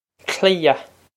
Klee-uh
This is an approximate phonetic pronunciation of the phrase.